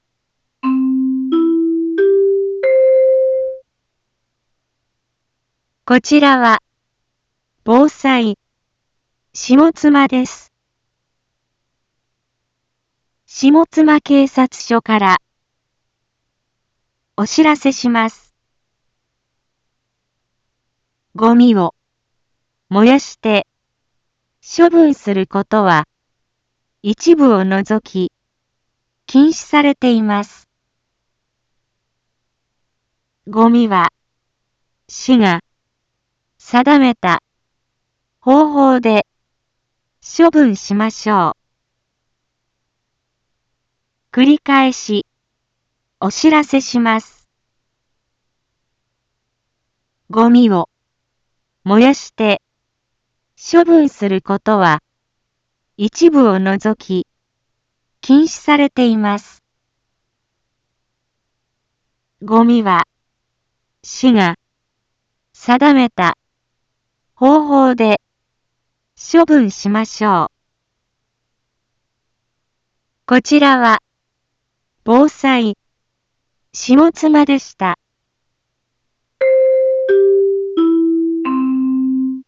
Back Home 一般放送情報 音声放送 再生 一般放送情報 登録日時：2023-08-25 10:01:25 タイトル：ごみの野焼き禁止（啓発放送） インフォメーション：こちらは、防災、下妻です。